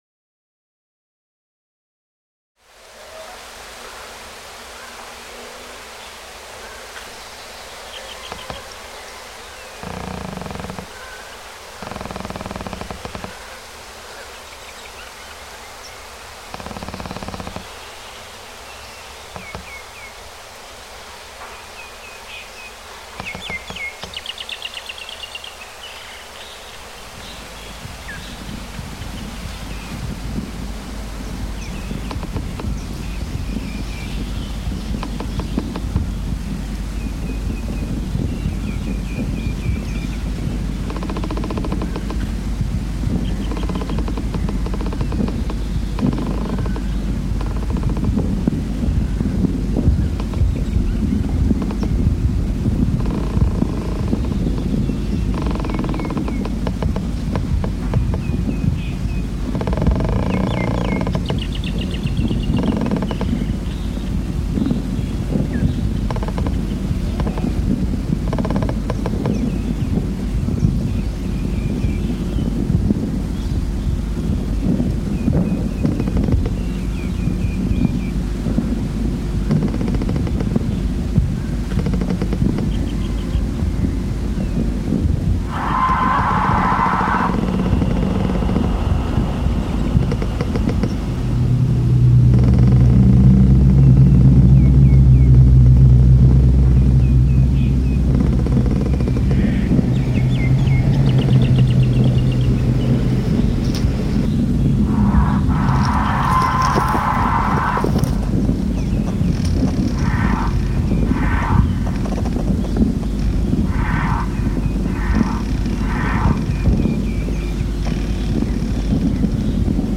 Thai birdsong reimagined